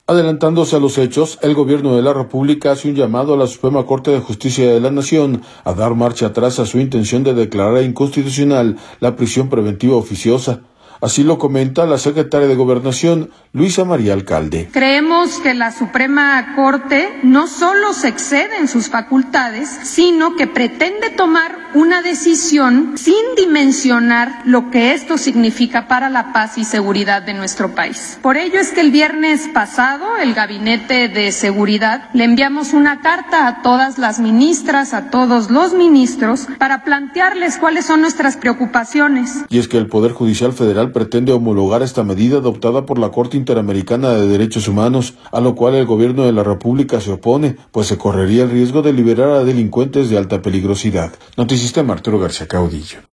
Adelantándose a los hechos, el Gobierno de la República hace un llamado a la Suprema Corte de Justicia de la Nación a dar marcha atrás a su intención de declarar inconstitucional la prisión preventiva oficiosa, así lo comenta la secretaria de Gobernación, Luisa María Alcalde.